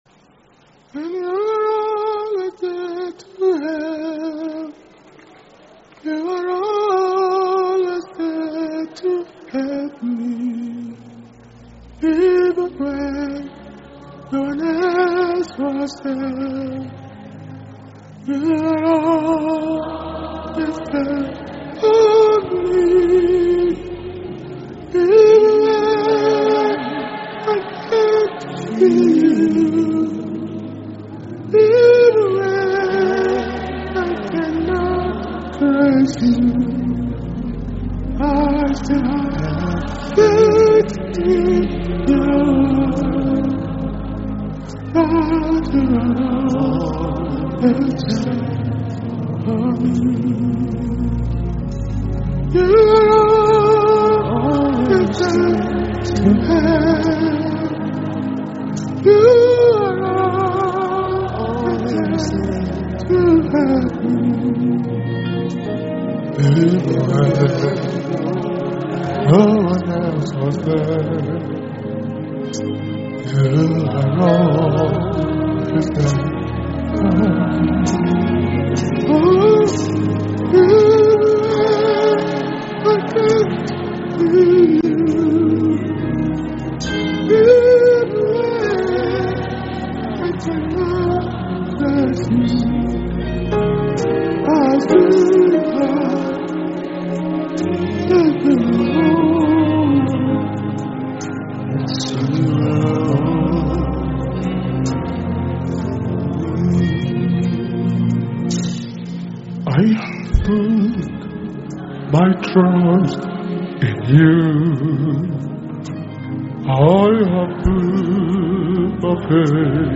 Financial Wisdom Message mp3